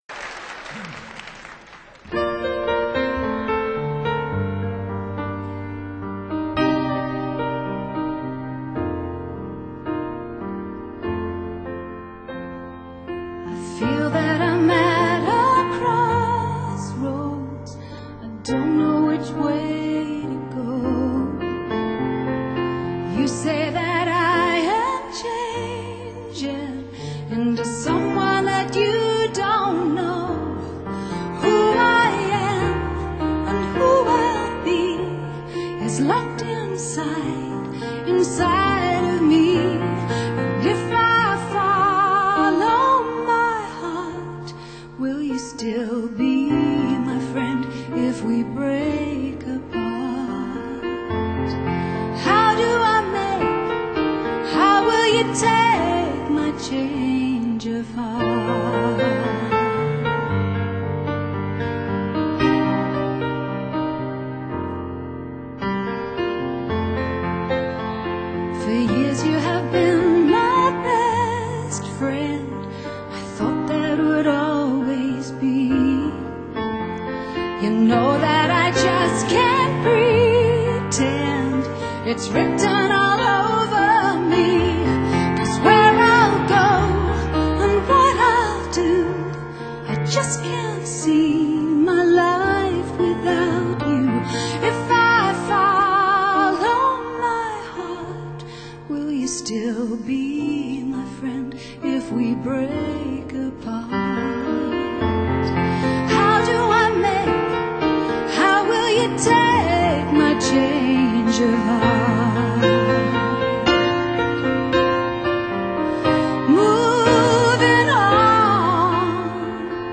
专辑主要是在2000年3月的盐湖城音乐会上现场录制的，再一次显示了他不一般的艺术天份。